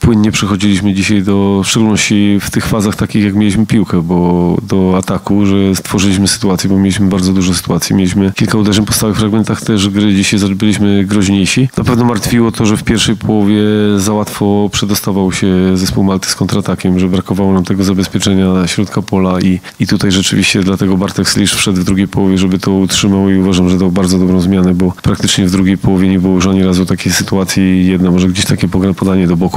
podsumował selekcjoner Polski – Michał Probierz